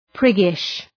Προφορά
{‘prıgıʃ}